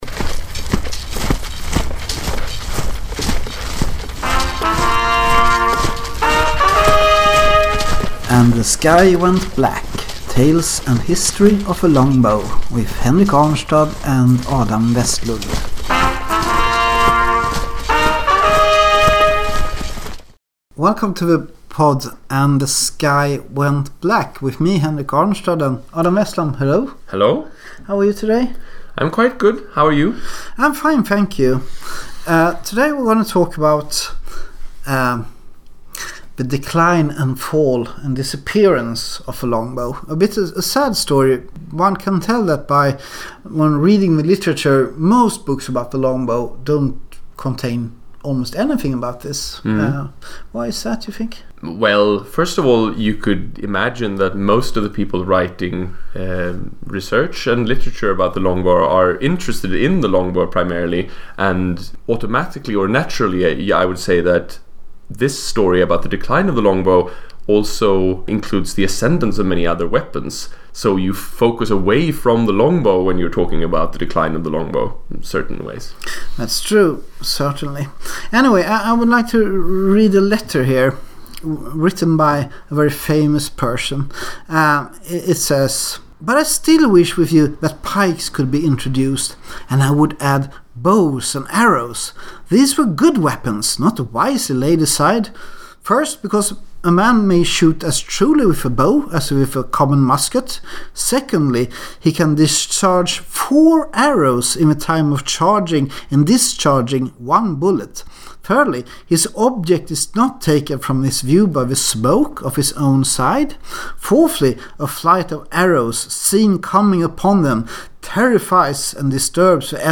Being Swedish, we are sorry for our horrible English.